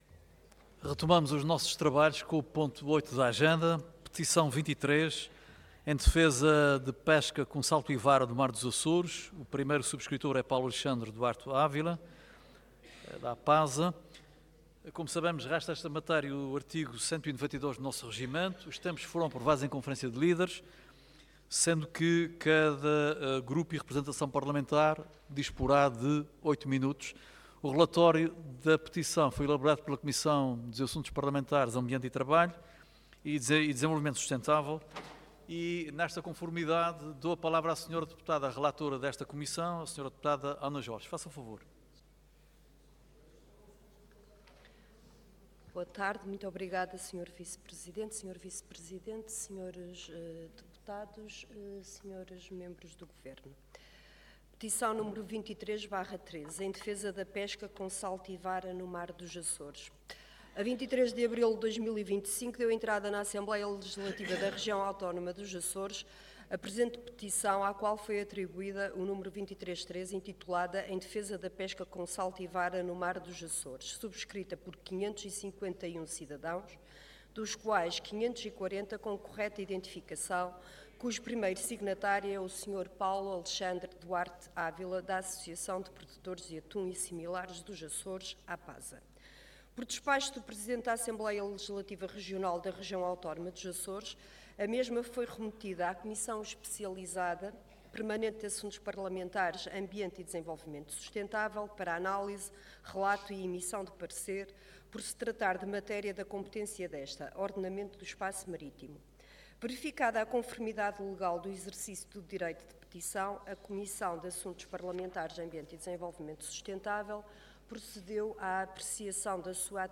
Website da Assembleia Legislativa da Região Autónoma dos Açores
Intervenção
Orador Ana Jorge Cargo Relatora